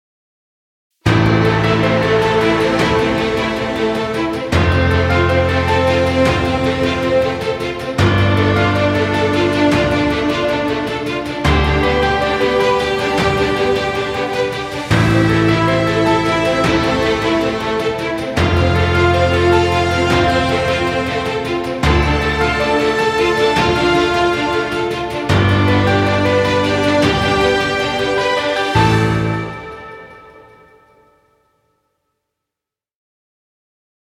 Epic track for piano, choir and orchestra.
Cinematic dramatic music. Trailer music.